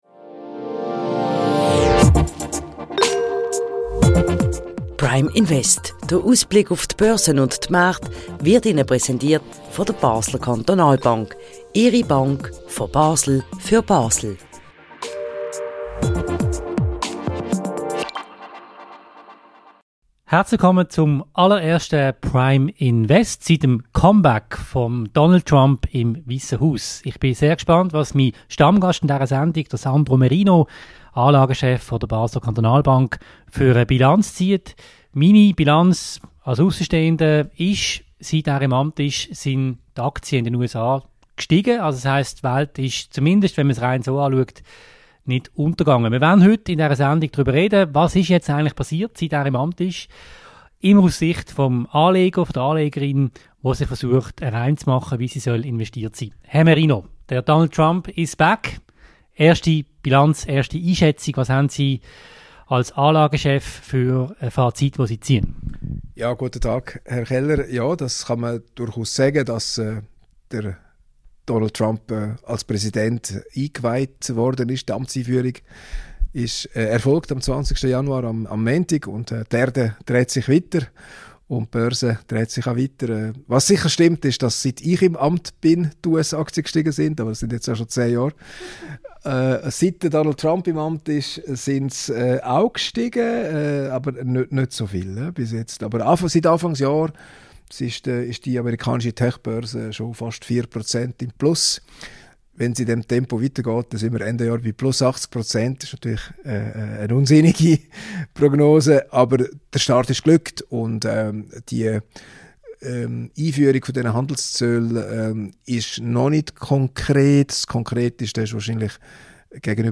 Erweiterte Suche Trump is back: Wie haben die Börsen reagiert? vor 1 Jahr Soll man jetzt noch mehr in Tech-Aktien umschichten, weil der US-Präsident stark in Künstliche Intelligenz investieren will? Das Interview